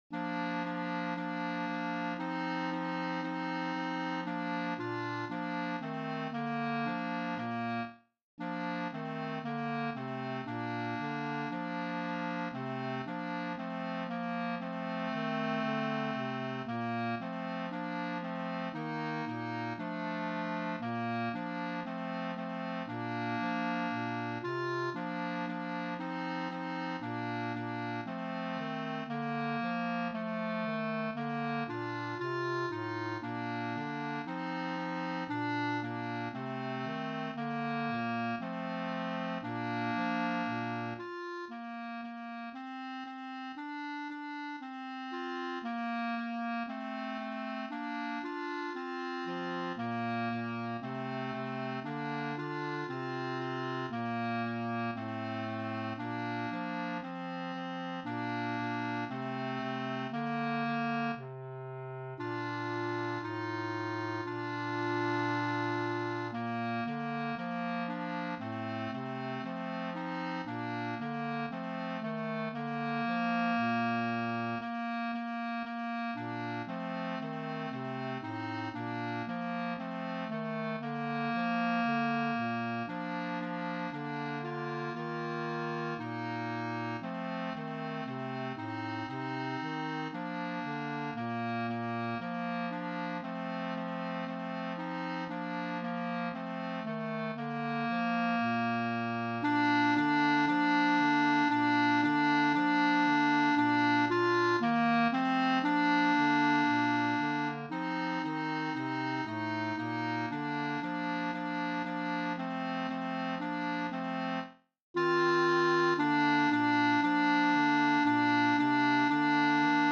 108M1 Trio for any instruments $10.00